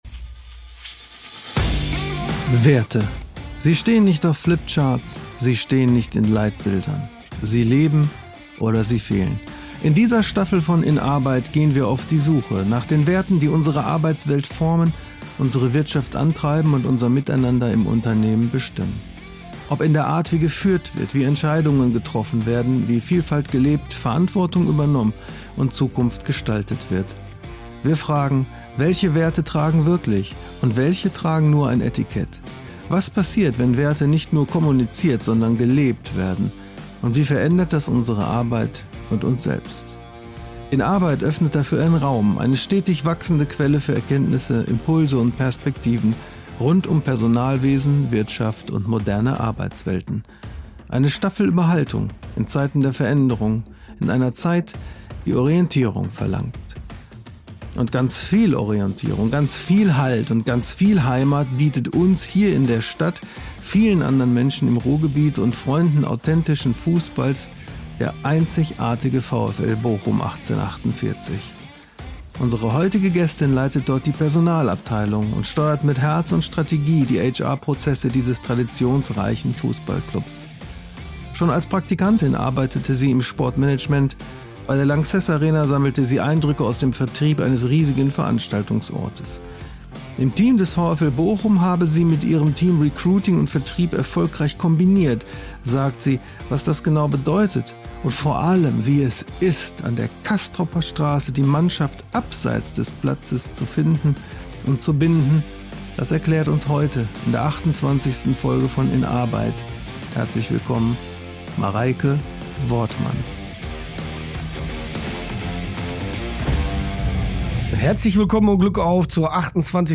Wie schafft man es, an der Castroper Straße die Mannschaft abseits des Platzes dermaßen gut zu finden und zu binden? Ein Gespräch über offene Ohren und Herzen, gegenseitiges Vertrauen und eine Erlebniskultur, die wirklich spürbar macht, wo und für wen man arbeitet.